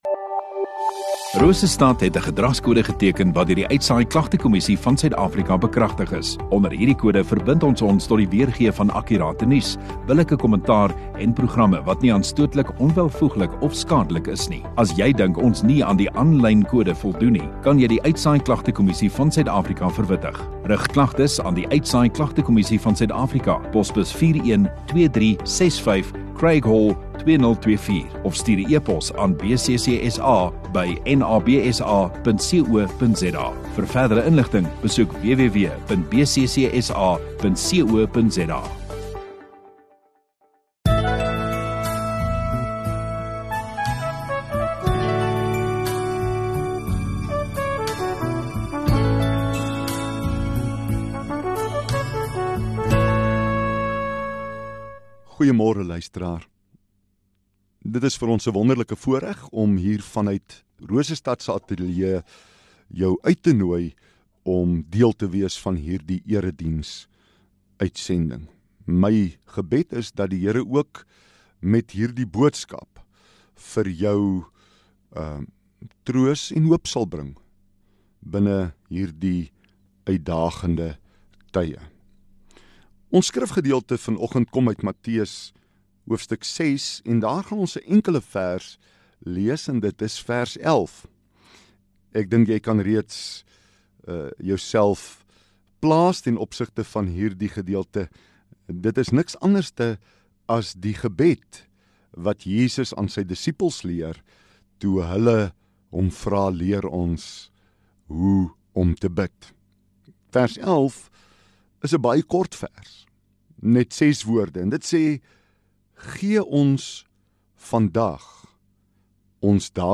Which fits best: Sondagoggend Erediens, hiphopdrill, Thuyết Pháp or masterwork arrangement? Sondagoggend Erediens